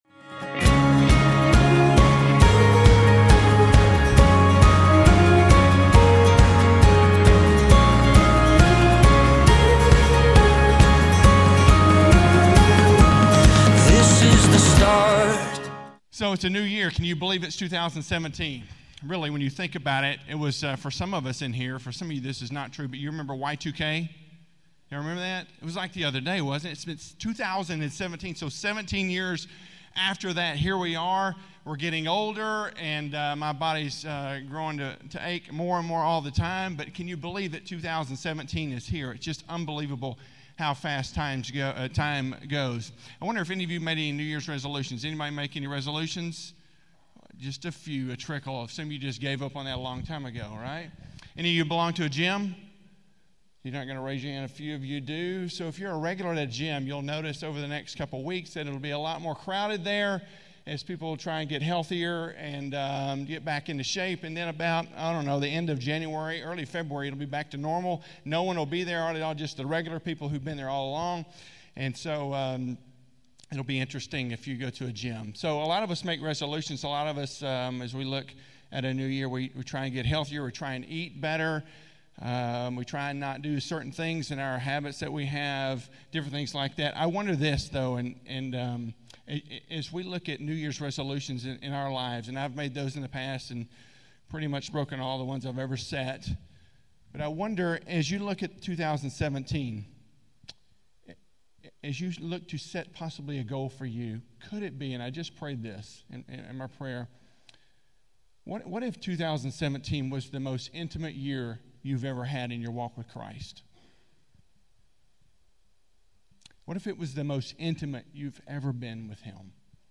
A message from the series "Single Sermon Messages."
Single Sermon Messages